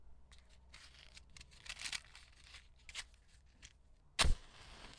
厨房的声音 " 火柴盒灯
描述：打开火柴盒，点燃火柴
Tag: 嘶嘶声 火柴盒 比赛